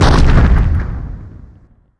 metinstone_drop2.wav